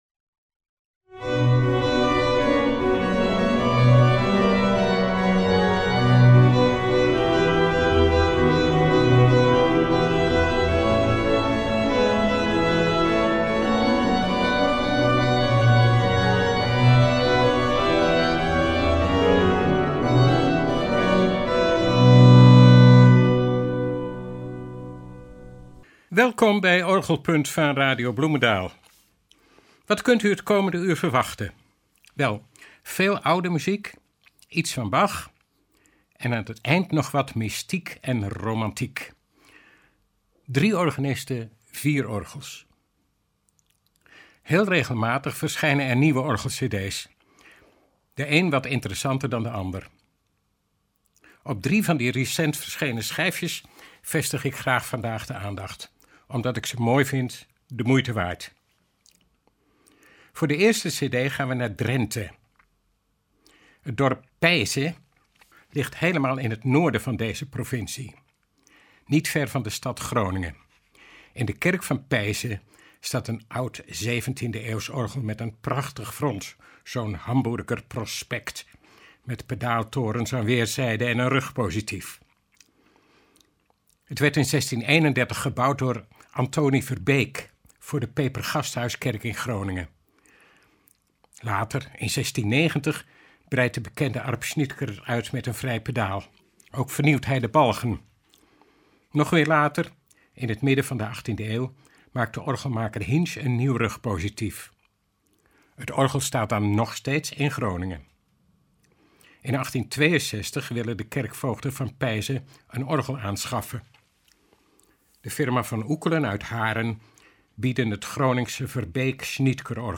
Mystieke kleuren, romantische muziek op romantische orgels.